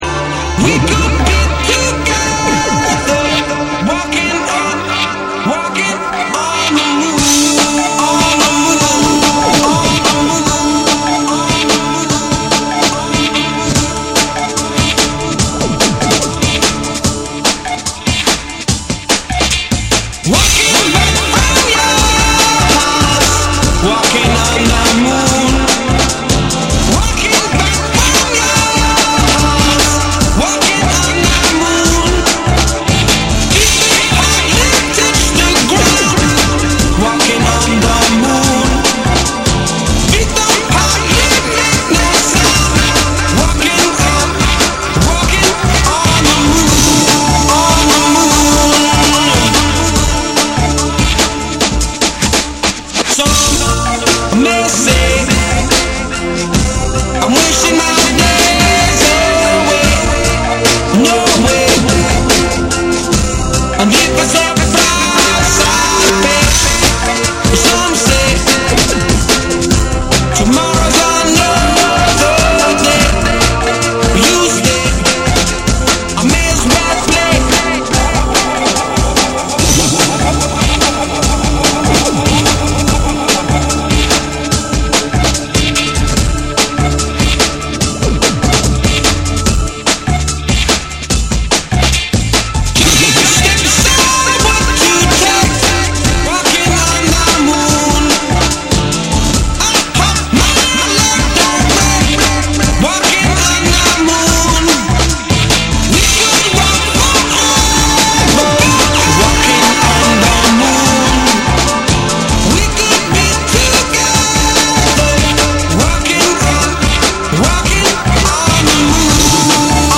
オリジナルの魅力を活かしつつ、ヒップホップ〜ブレイクビーツ〜ハウスの視点でアップデートされたクロスオーバーな一枚。
NEW WAVE & ROCK / BREAKBEATS / TECHNO & HOUSE